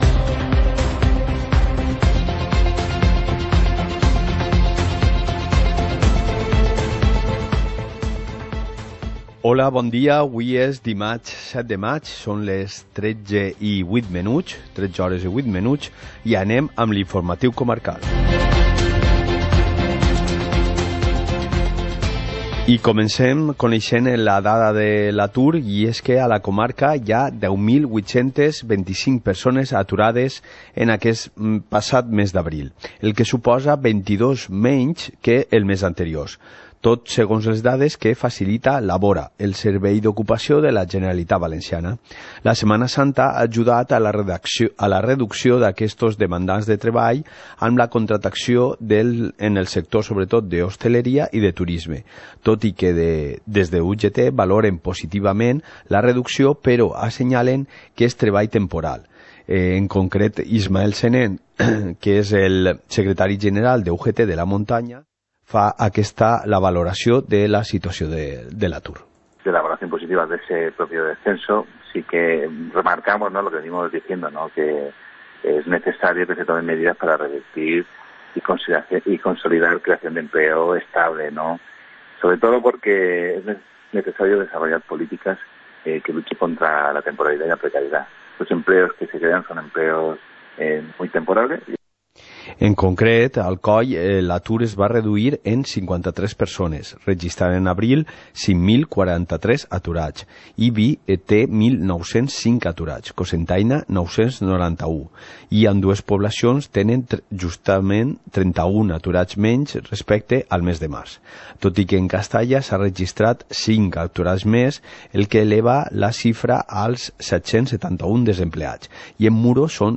Informativo comarcal - martes, 07 de mayo de 2019